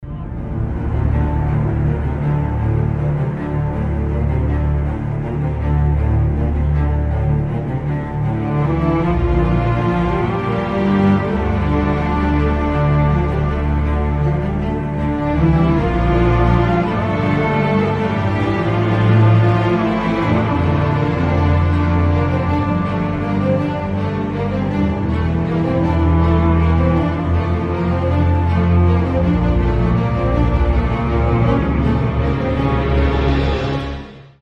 спокойные
без слов
оркестр
эпичные